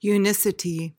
PRONUNCIATION:
(yoo-NIS-uh-tee)